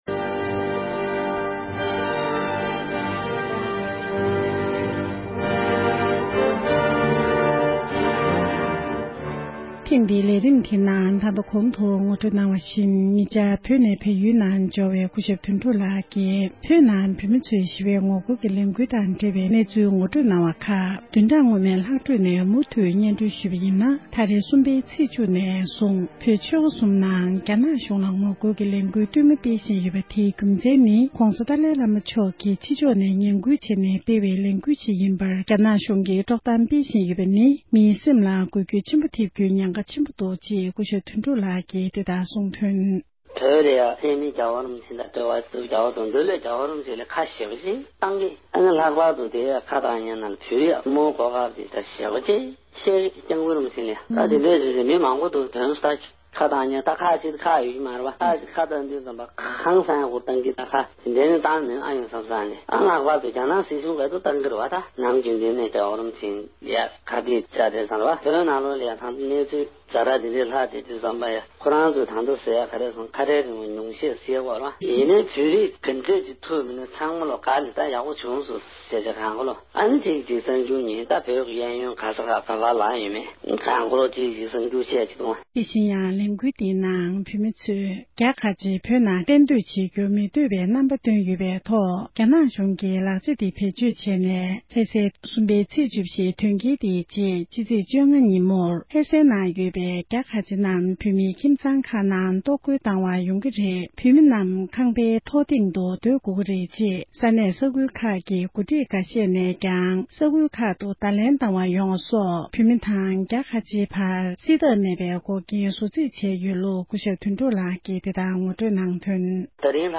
ཞལ་པར་བརྒྱུད་གནས་འདྲི་ཞུས་པར་གསན་རོགས་གནང༌༎